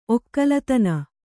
♪ okkalatana